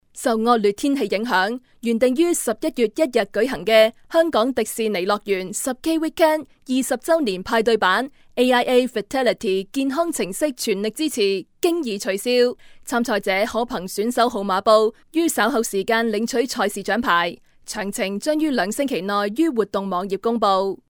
Commercial Spots